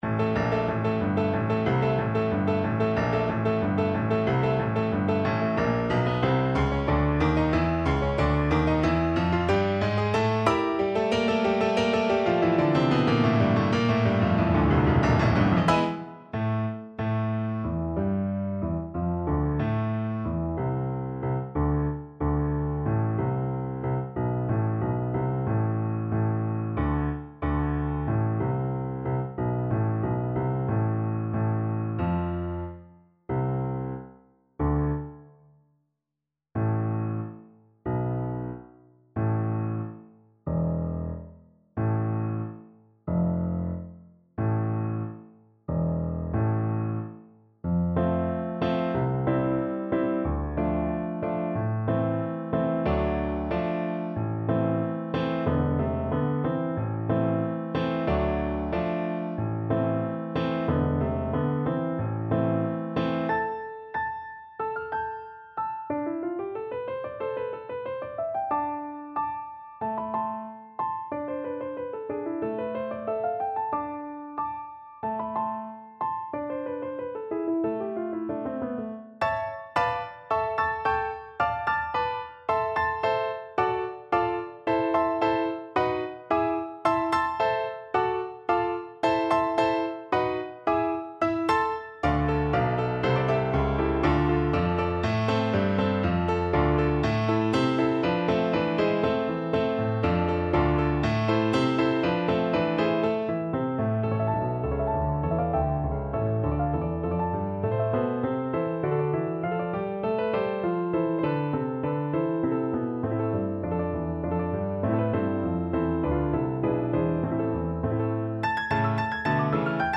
Allegro =92 (View more music marked Allegro)
2/4 (View more 2/4 Music)
Classical (View more Classical French Horn Music)